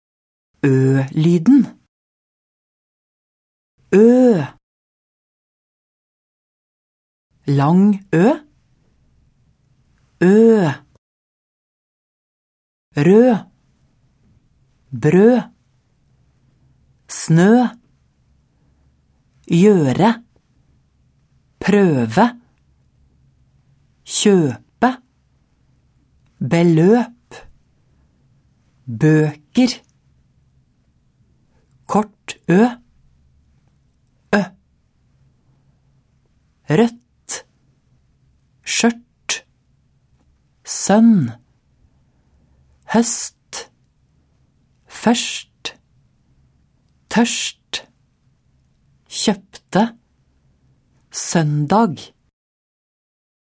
Uttale: Ø-lyden (s. 104-105)